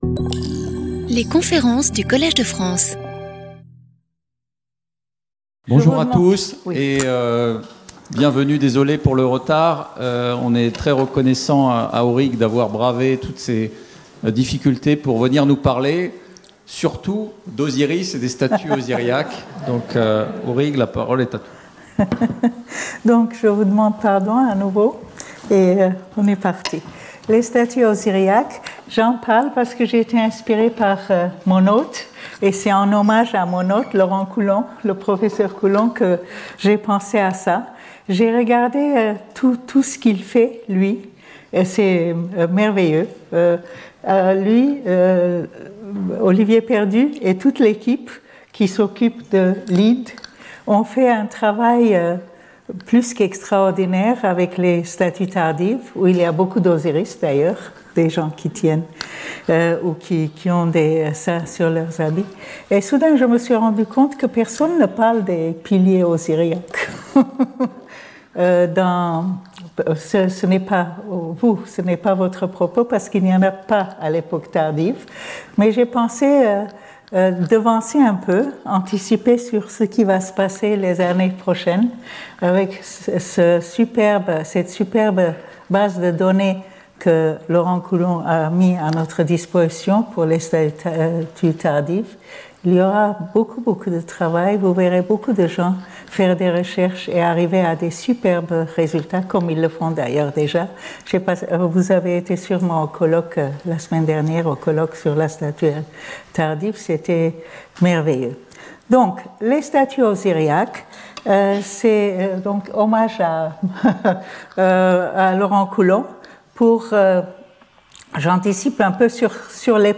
Conférencier invité